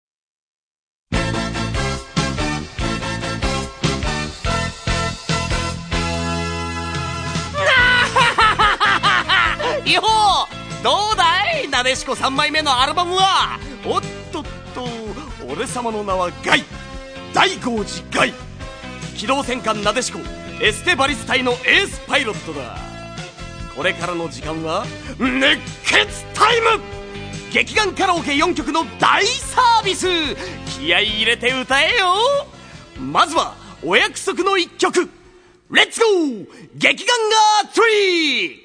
Seki Tomokazu-sanGai Daigohji's seiyuu (or voice actor) is none other than Seki Tomokazu, my third favorite seiyuu.
These sounds are from the Gekiganger Karaoke section of the soundtrack where Gai introduces each song for the karaoke section.
Gai's Hotblooded Talk #1 - Gai introduces the Karaoke Section.
gai_speak_1.mp3